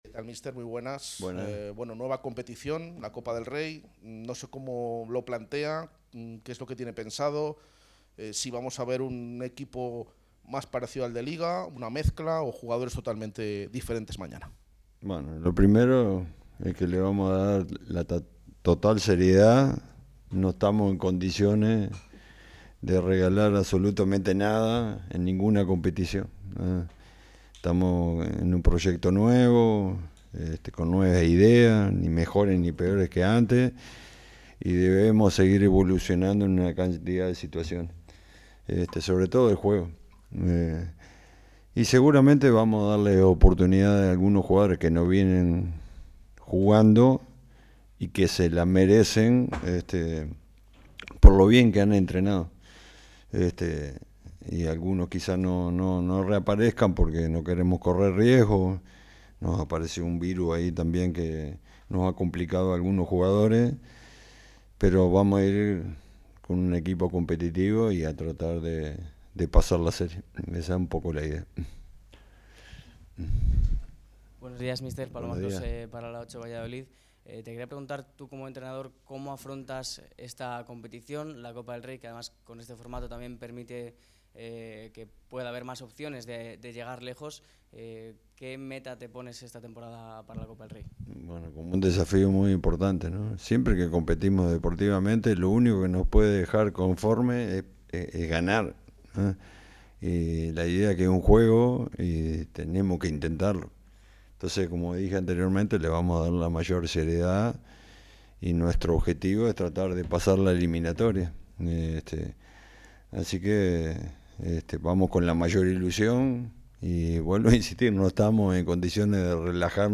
Guillermo Almada habló de la importancia que tiene la Copa del Rey para continuar con la evolución del equipo